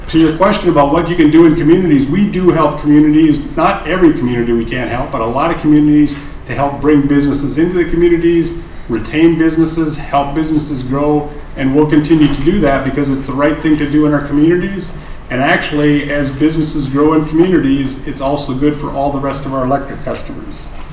Alliant Energy Officials listen to audience comments at the Clinton hearing
Local residents lined up to speak out against a proposed Alliant Energy gas and electric rate increase at an Iowa Utility Board public hearing.